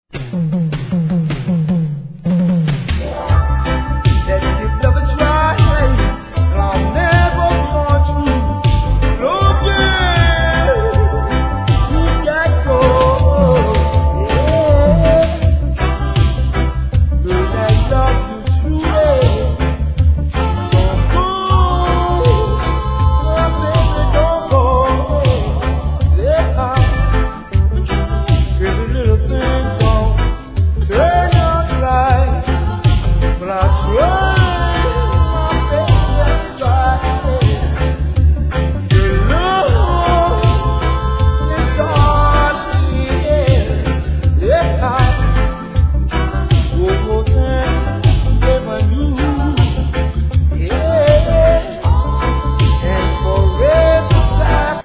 REGGAE
凝ったバック・コーラスで聴かせるマイナー・ヴォーカル物